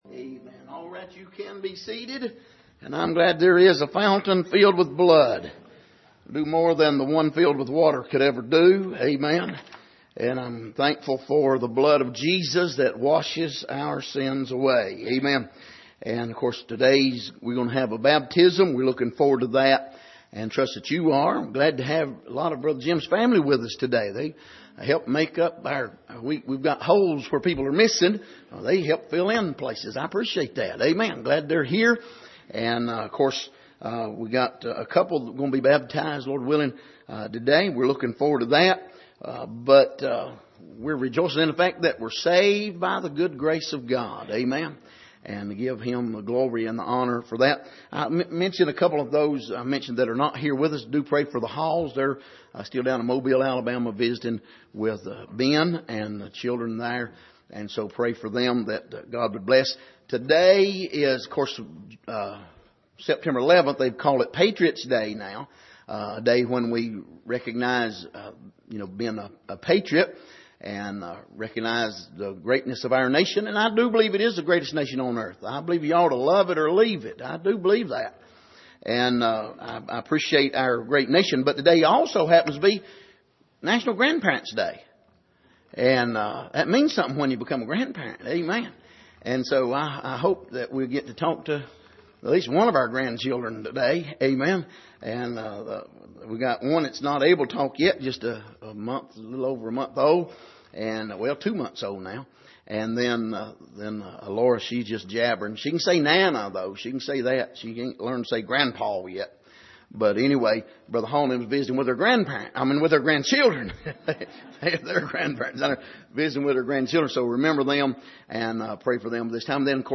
Miscellaneous Passage: Matthew 3:1-17 Service: Sunday Morning Truths About Baptism « The Invitation To Pray